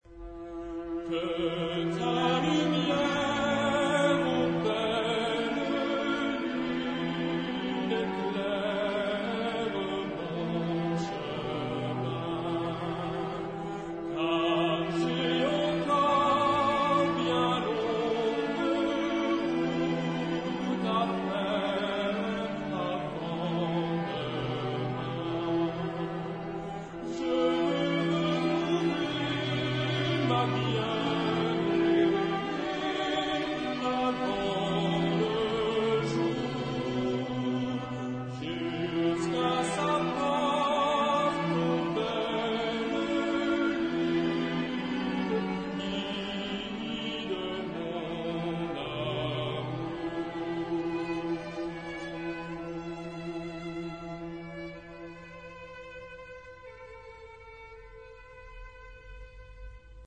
Genre-Style-Forme : Profane ; Populaire
Caractère de la pièce : contemplatif
Type de choeur : SATB  (4 voix mixtes )
Solistes : Ténor (1) OU Baryton (1)  (1 soliste(s))
Tonalité : mi mode de ré
Origine : Pays Basque